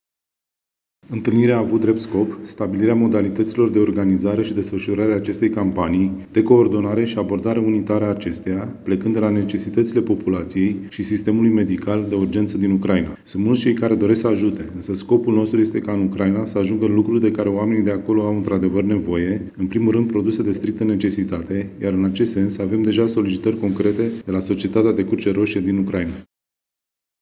Pentru a nu se ajunge în situația ca unele bunuri să fie în exces, iar altele deficitare, Instituția Prefectului a organizat o întâlnire cu principalii factori implicați. Prefectul județului, Cătălin Văsii: